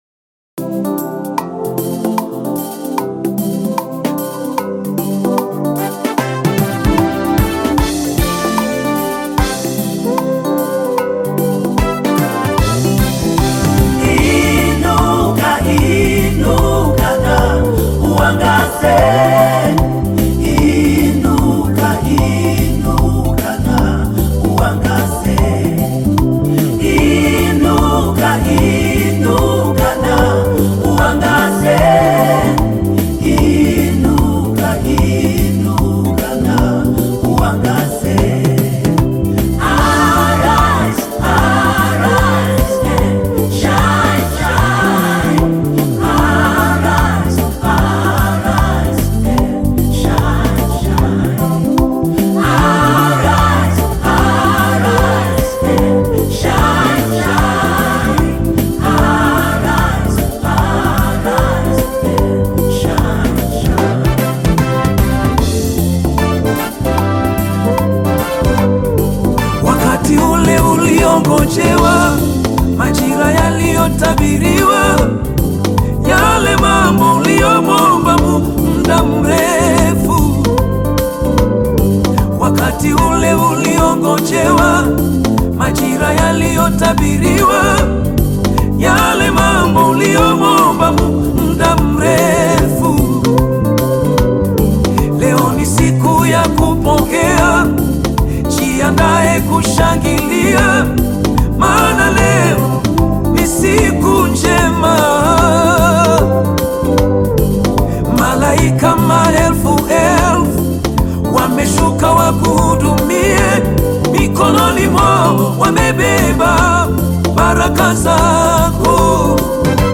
Tanzanian gospel singer and songwriter
gospel song